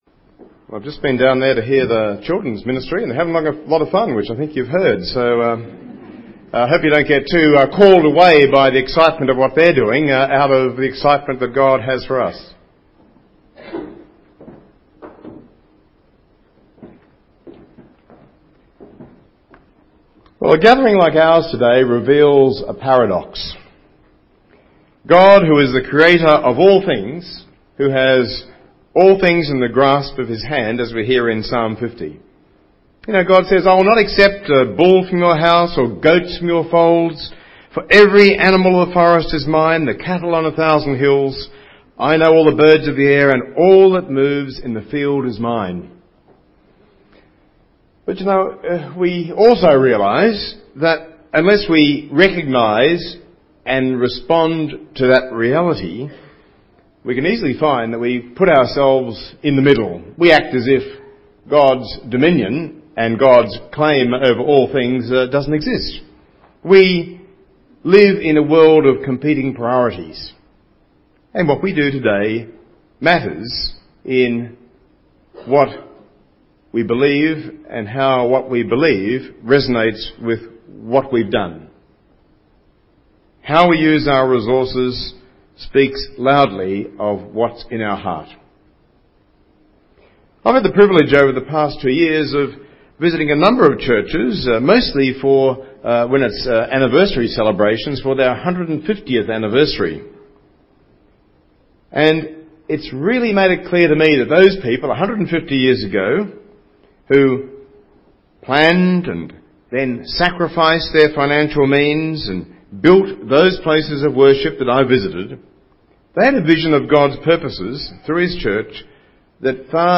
In this sermon, Archbishop Philip Freier speaks on the theme of 'Dedication Sunday' as part of the series 'Standalone Sermon'.